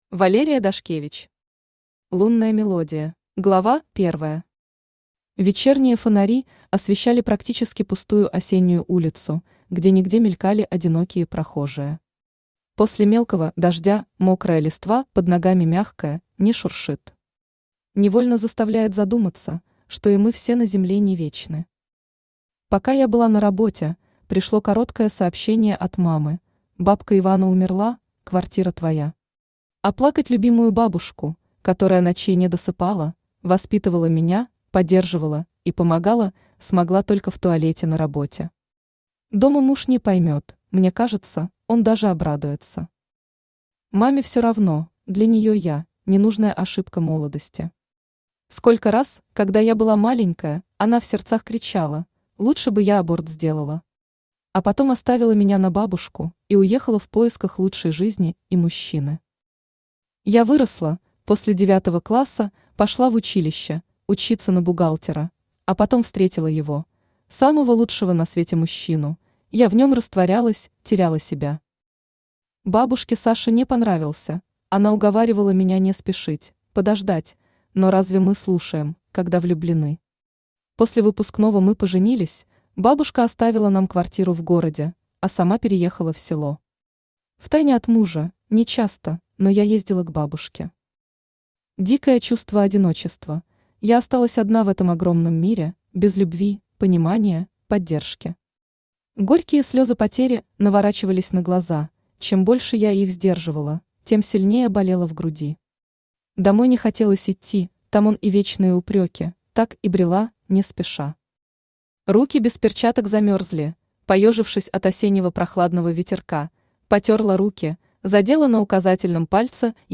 Аудиокнига Лунная мелодия | Библиотека аудиокниг
Aудиокнига Лунная мелодия Автор Валерия Дашкевич Читает аудиокнигу Авточтец ЛитРес.